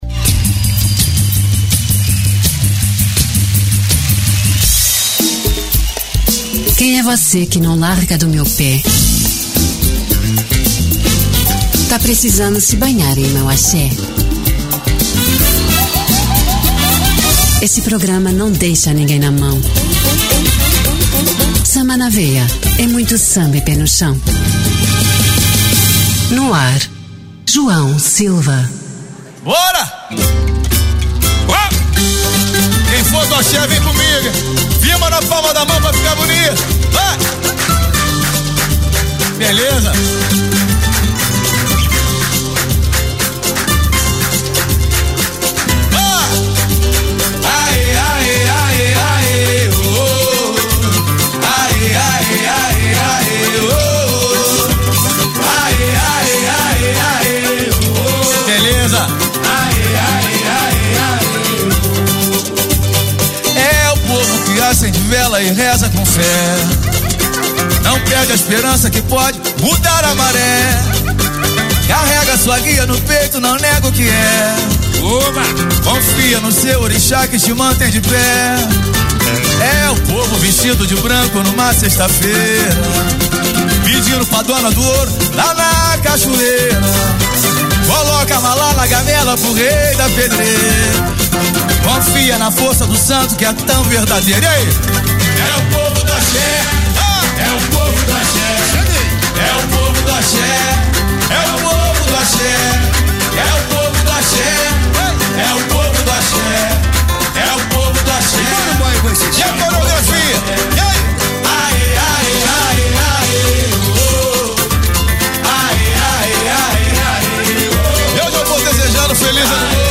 Muito Samba e pé no chão!